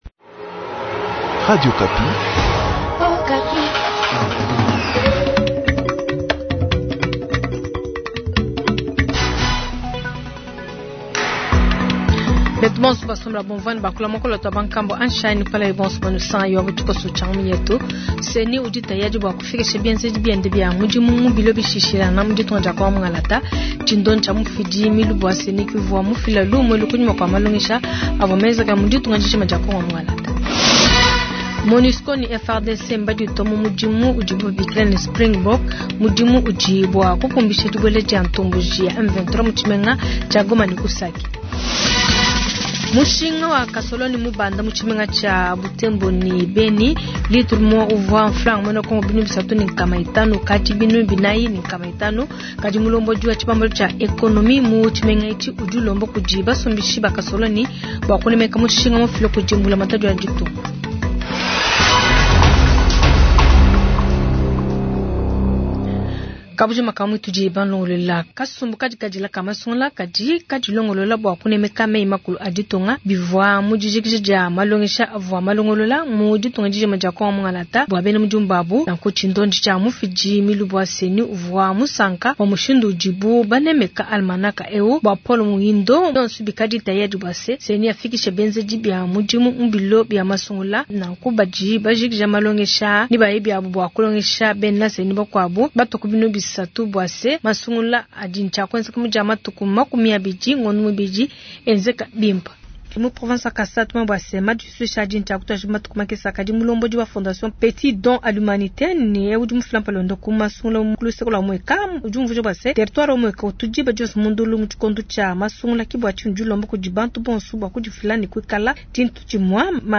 Journal Matin
0712-p-t-journal_tshiluba_matin_web.mp3